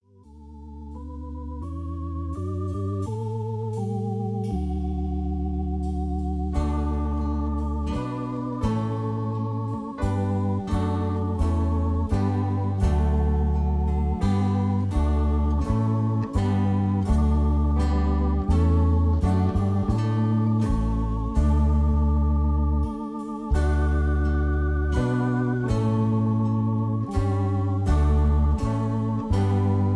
easy litstening, country rock, gospel, backing tracks